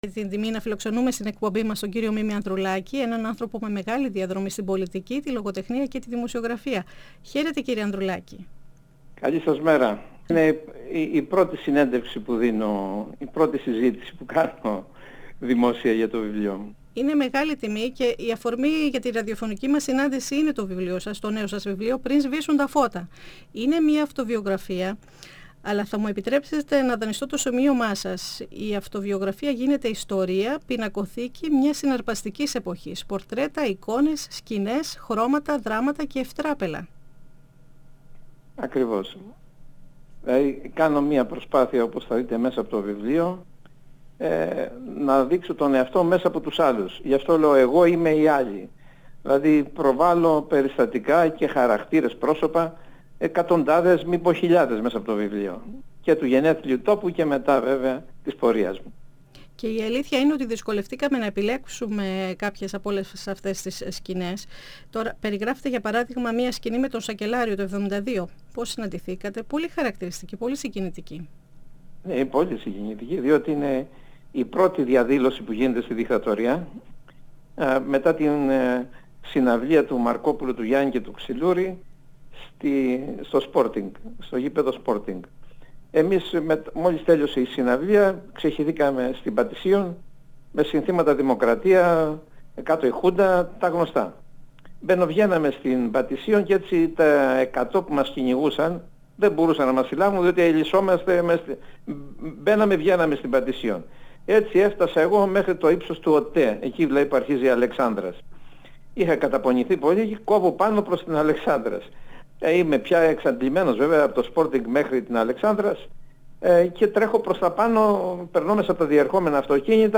Ο συγγραφέας αναφέρεται στα παιδικά και φοιτητικά του χρόνια, καταγράφοντας στιγμές- μικρές ιστορίες μιας συναρπαστικής εποχής. 102FM Συνεντεύξεις ΕΡΤ3